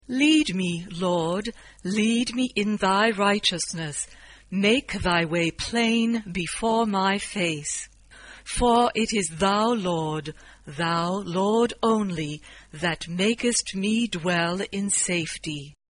SAB (3 voces Coro mixto) ; Partitura de coro.
Sagrado. Arreglo coral.
Tonalidad : re mayor